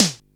Index of /musicradar/essential-drumkit-samples/DX:DMX Kit
DX Snare 01.wav